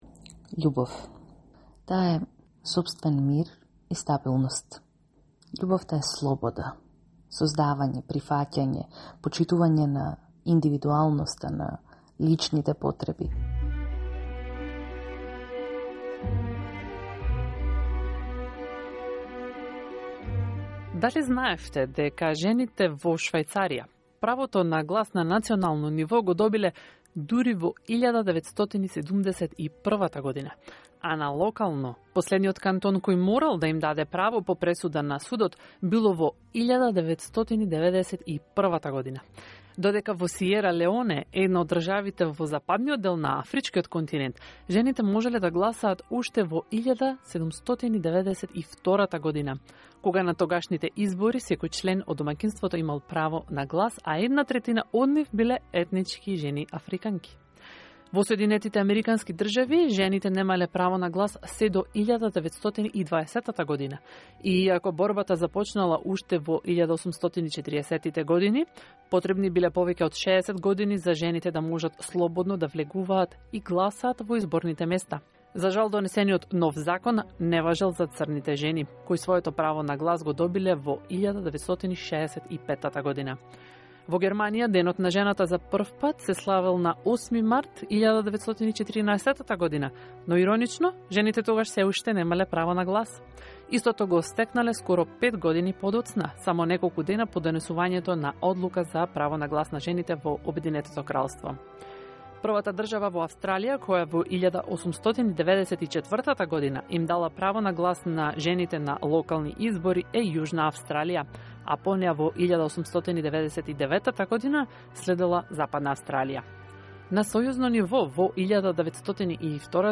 Seven Macedonian women discuss Love Source: Facebook